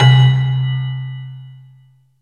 CELESTA LM 4.wav